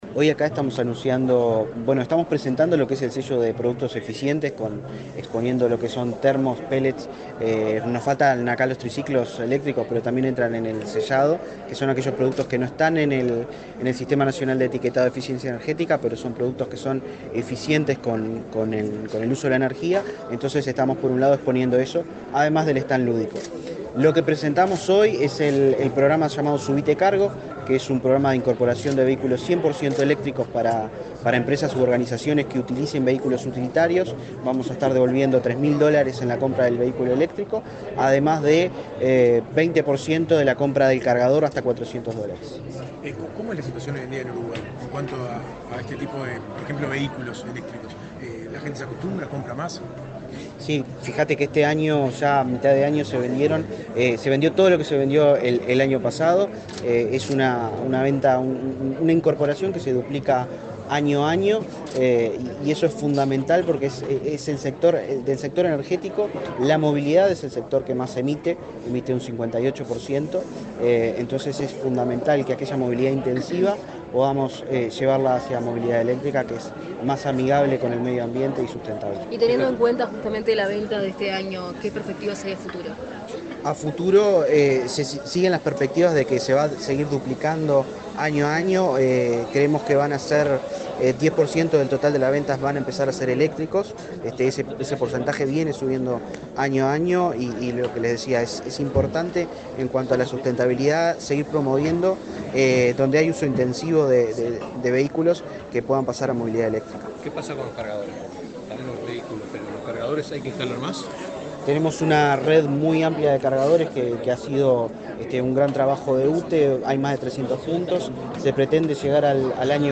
Declaraciones del director nacional de Energía, Christian Nieves
El director nacional de Energía, Christian Nieves, participó, este viernes 6, en la inauguración del stand de eficiencia energética del Ministerio de